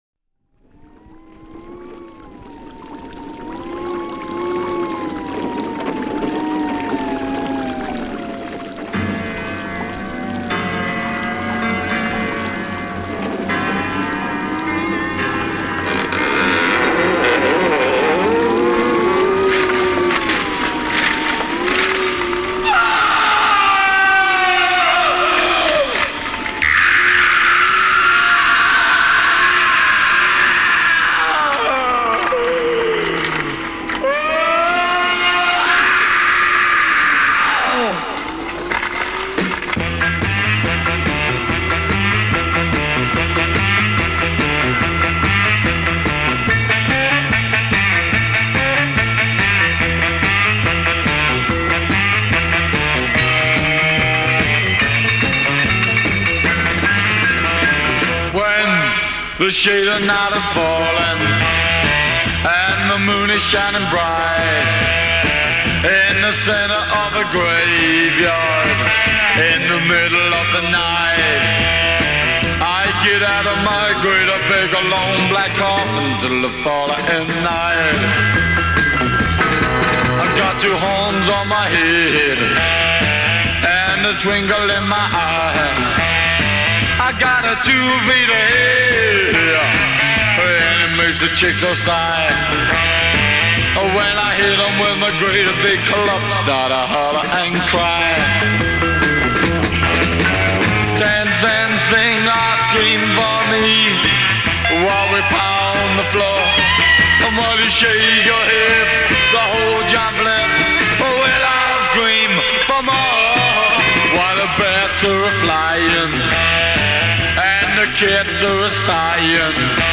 tenor saxophone
A intro 2 0:40 12 sax solo over New Orleans-style blues vamp
The band compensates. a
A verse sax and guitar solos x
coda more sound effects and fade e